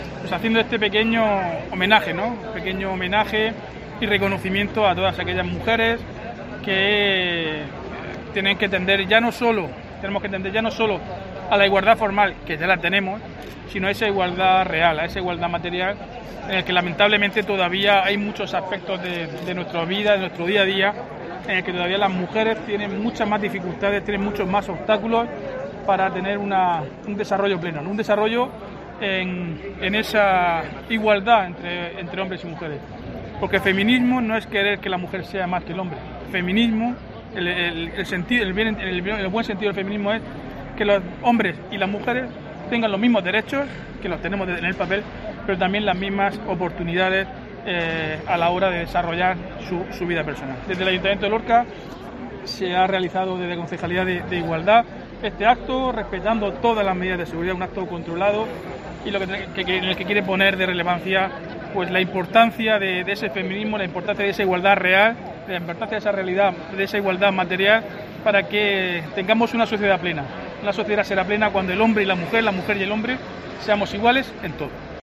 Diego José Mateos, alcalde de Lorca sobre 8M